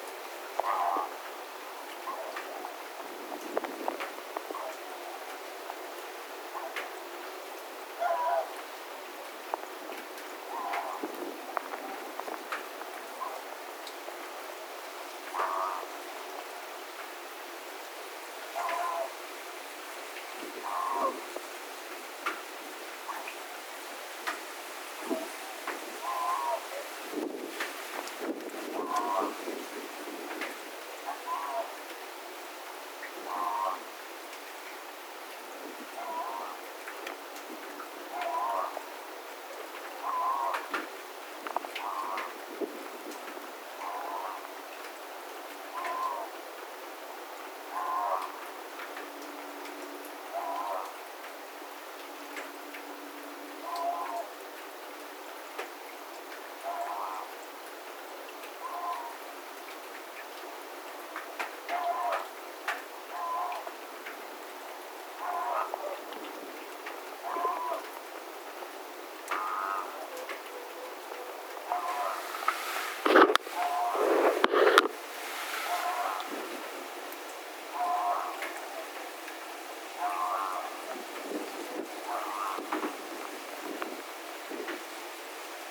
nuoren laulujoutsenen ääntelyä
nuoren_laulujoutsenen_aantelya_iso_poikanen_perheensa_kanssa_ilm_syntymapaikallaan.mp3